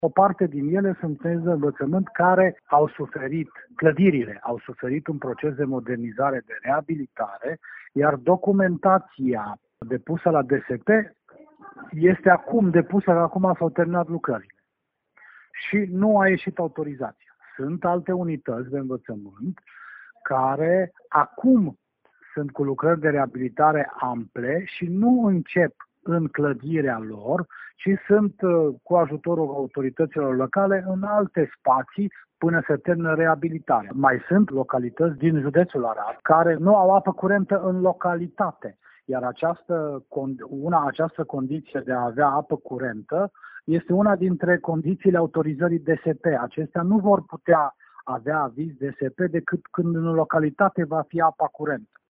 inspector-scolar-arad.mp3